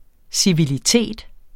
Udtale [ siviliˈteˀd ]